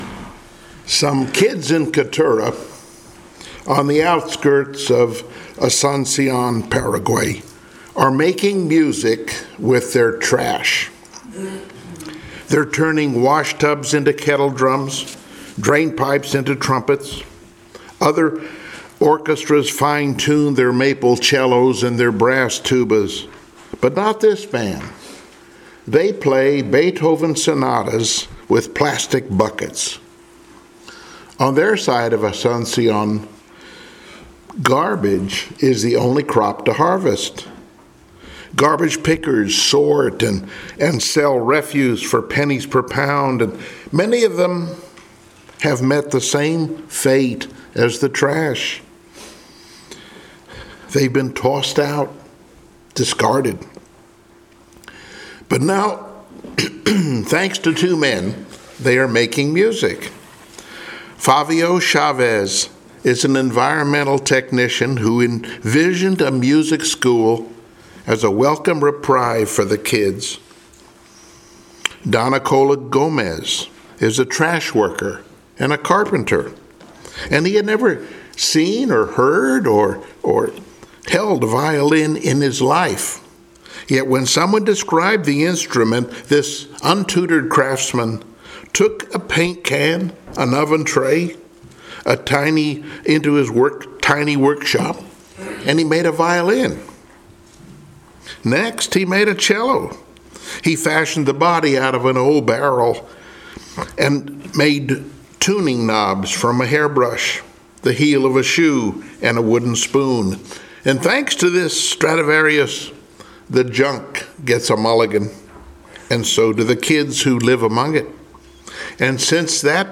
Passage: Joshua 2 Service Type: Sunday Morning Worship